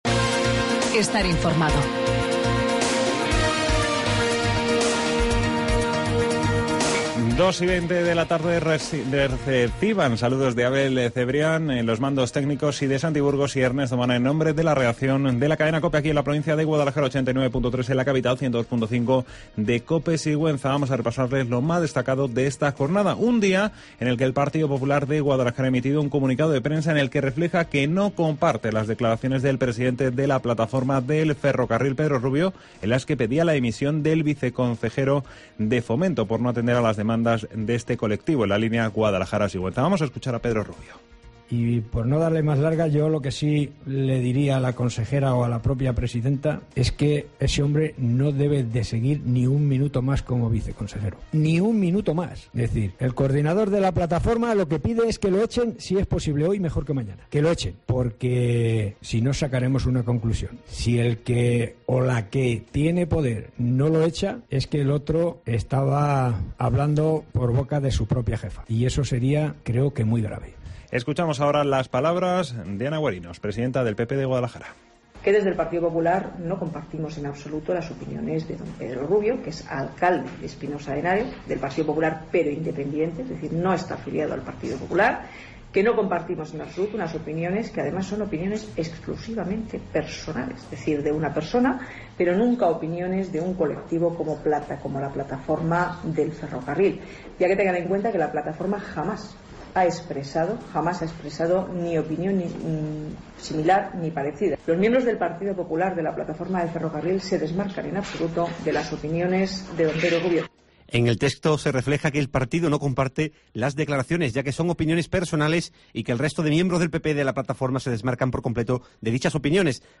Informativo Guadalajara 19 DE junio